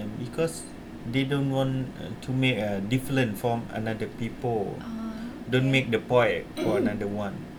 S1 = Brunei female S2 = Laos male
Intended Word: point Heard as: poet Discussion: There is no [n] or [t] at end of the word.